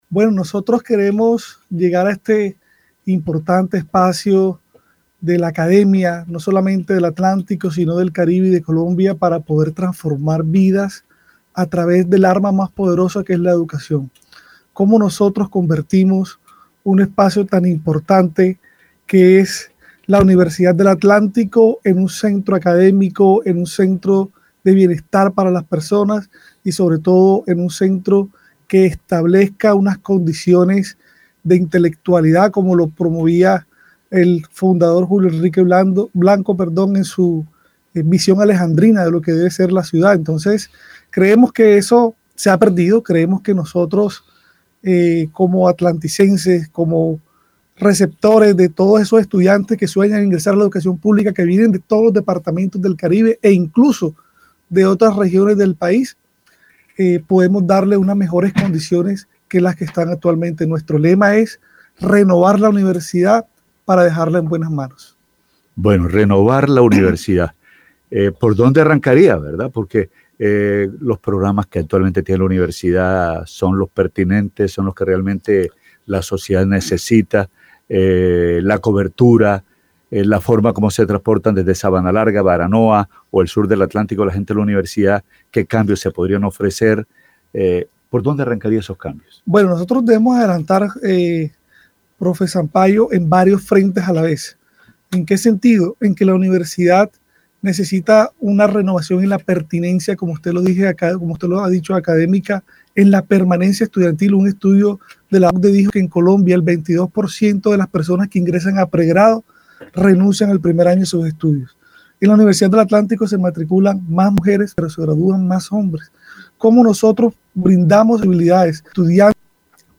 En entrevista con Noticias Ya!, el candidato expuso sus principales propuestas para transformar la institución. Su plan se enfoca en varios frentes, como la pertinencia académica, la permanencia estudiantil y la mejora de la infraestructura.